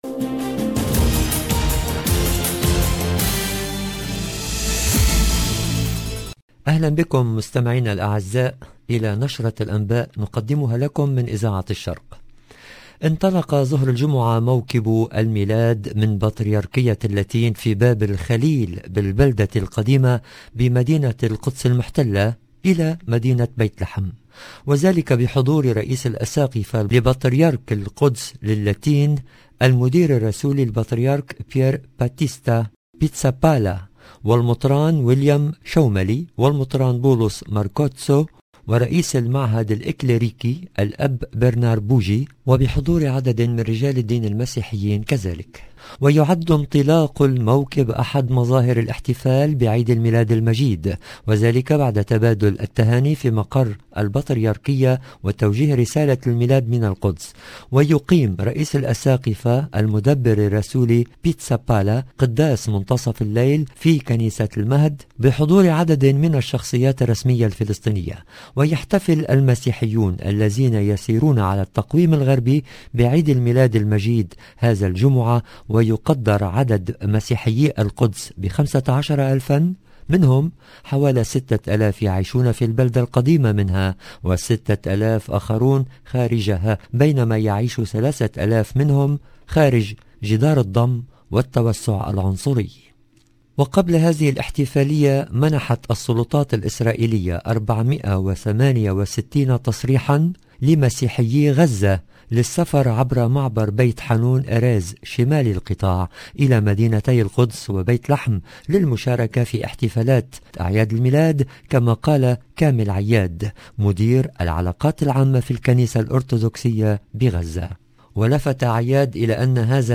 EDITION DU JOURNAL DU SOIR EN LANGUE ARABE DU 24/12/2021